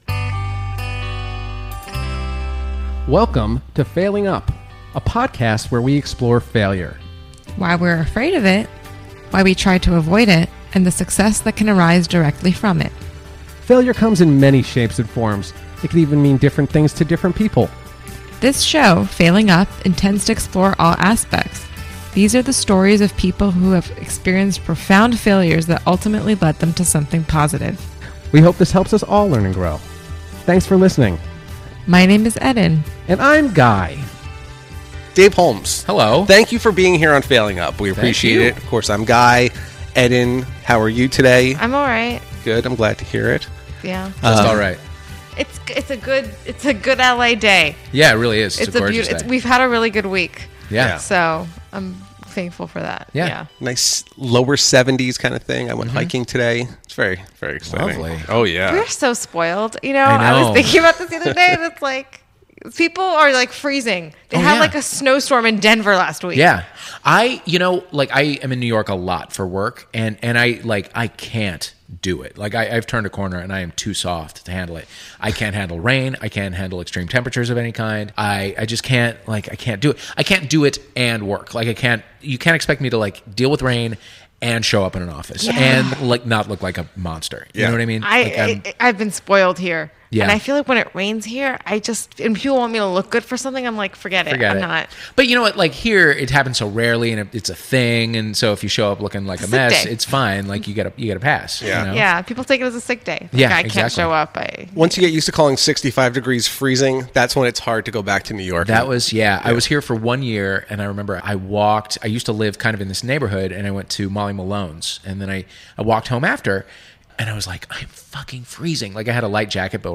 This week, Dave Holmes came to the studio and discussed his awesome story. As a high school freshman, Dave "snuck on" to a local college radio station.